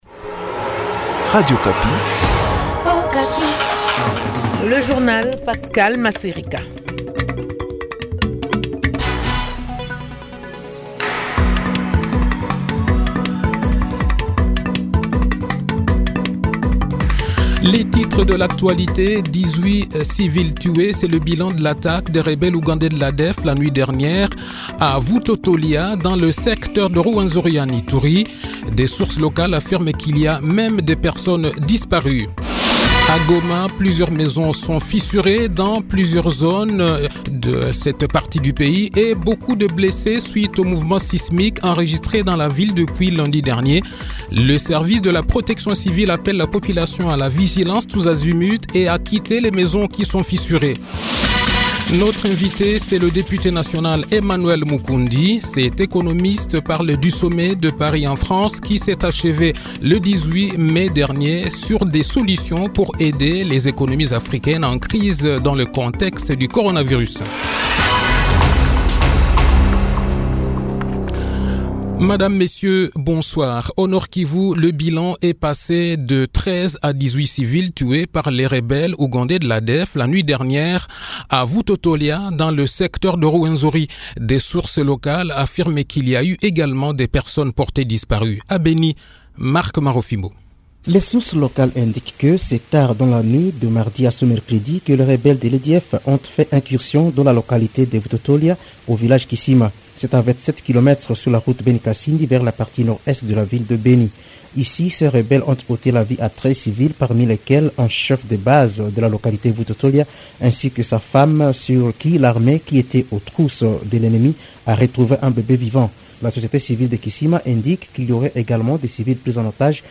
Le journal-Français-Soir